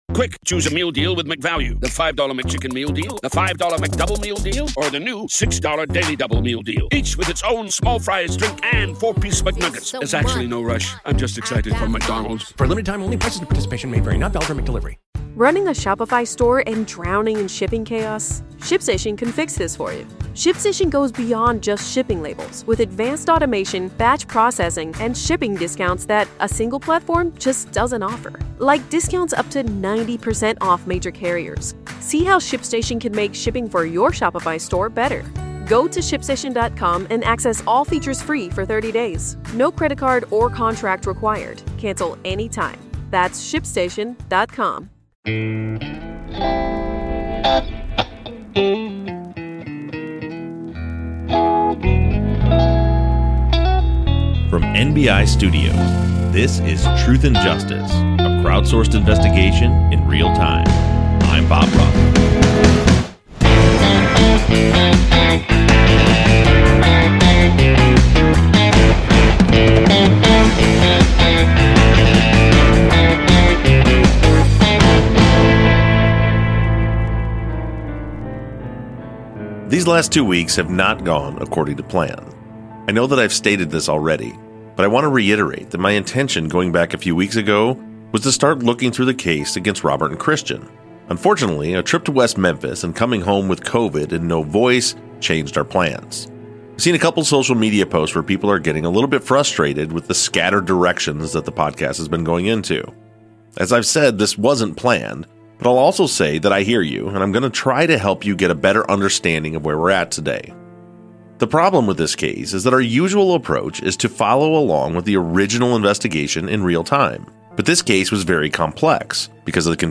We hear an interview